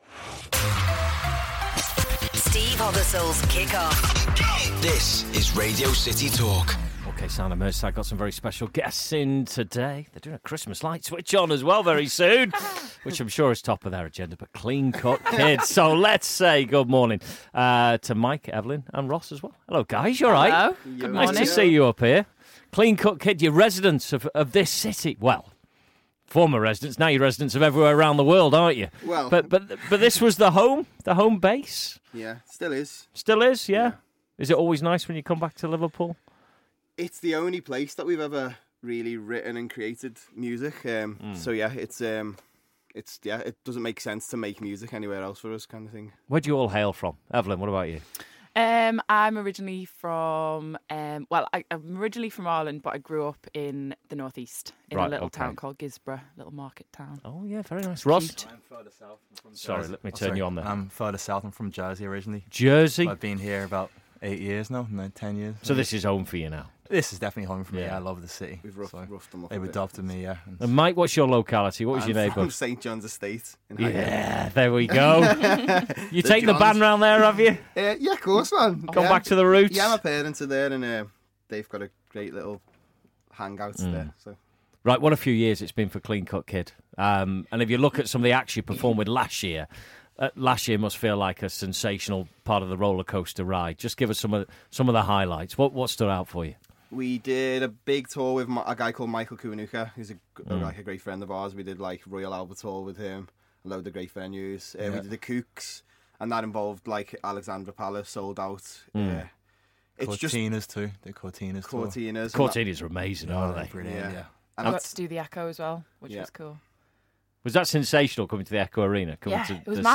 in the studio for a live session